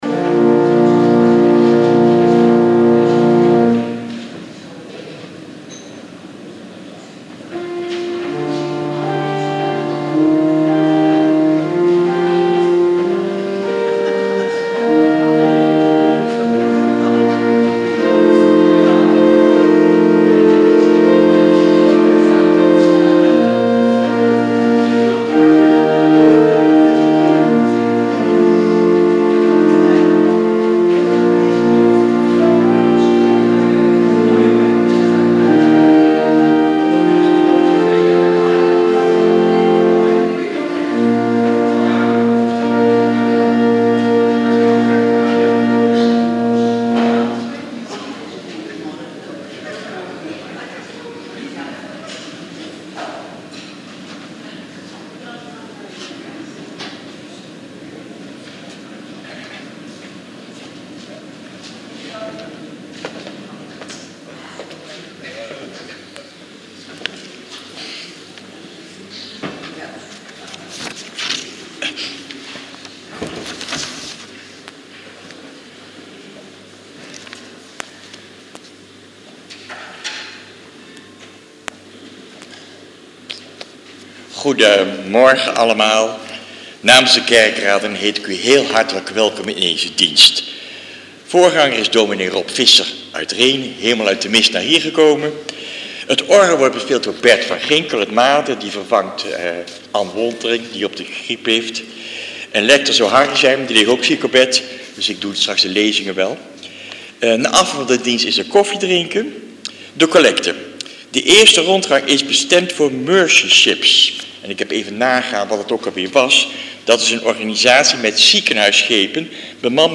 Dienst in Made